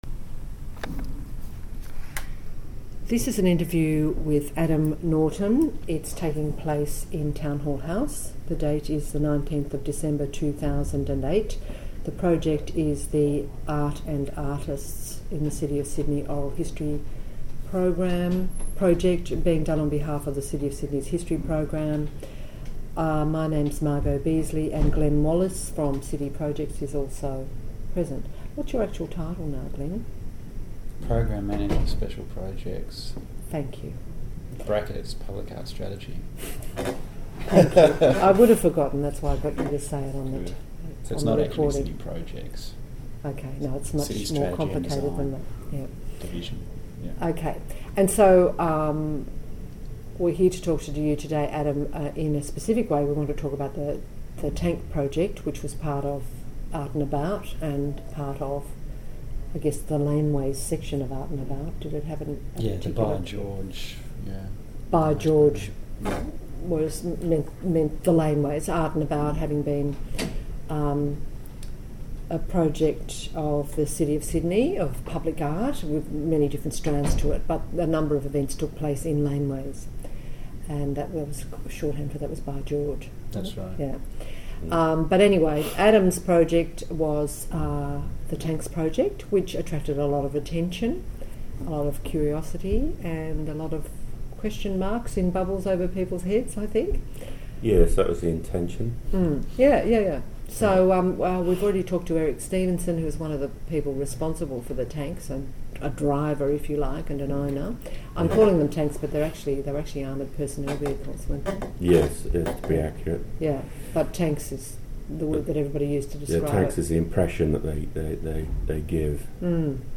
This interview is part of the City of Sydney's oral history theme: Art and Culture